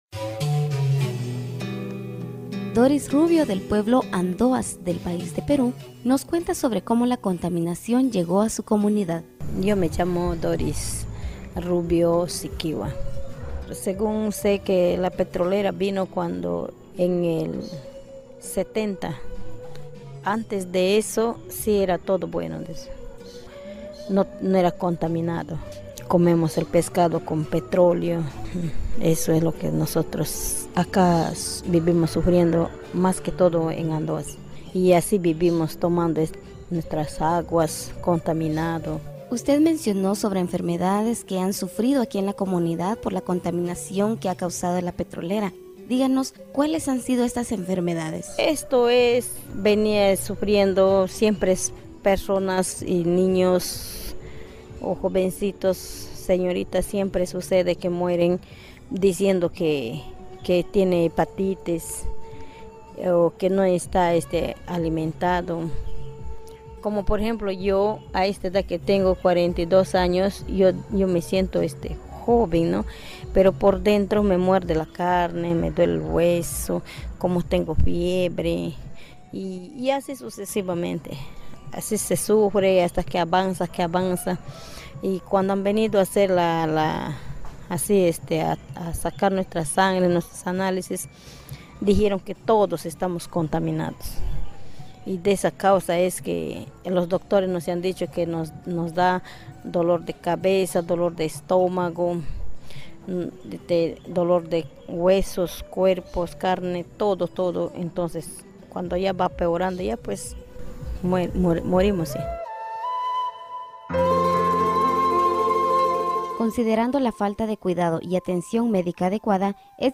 Documentary: